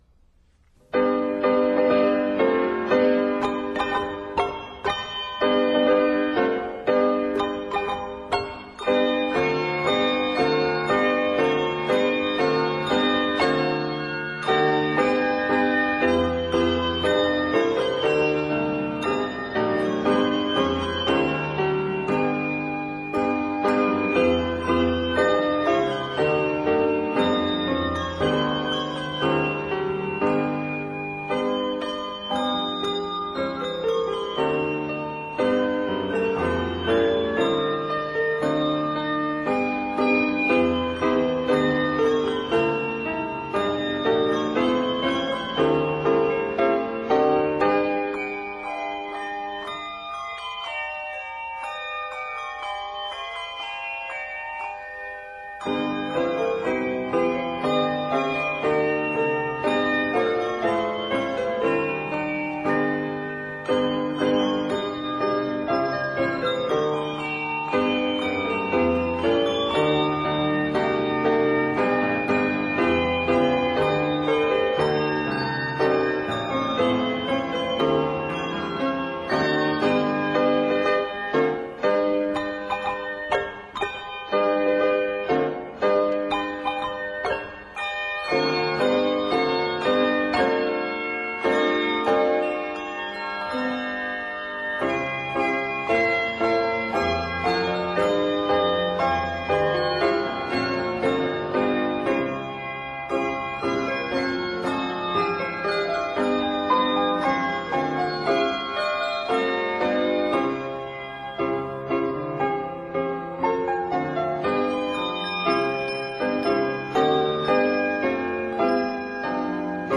to be rung with keyboard accompaniment